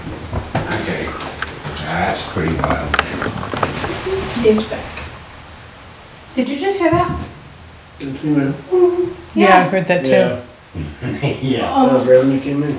To our great surprise, we captured an audible voice that originated from a room adjacent to where three investigators were monitoring our equipment on this night.